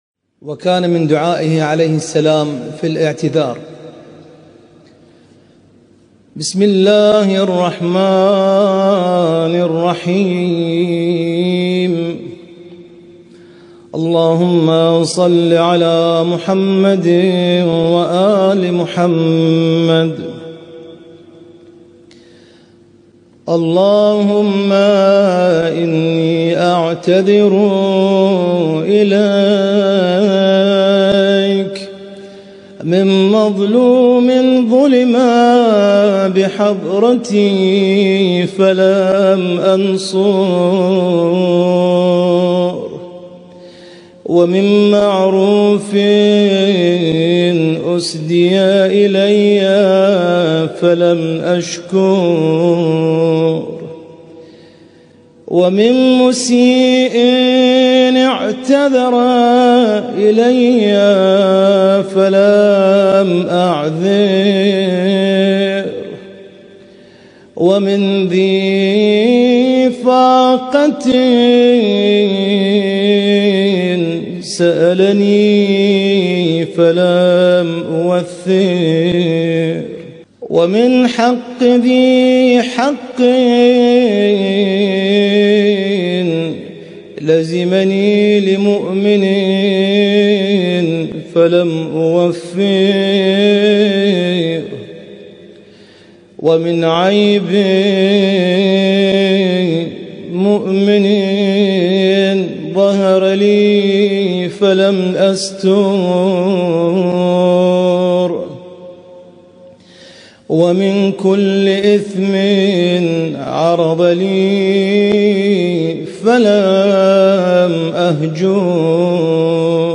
القارئ: القارئ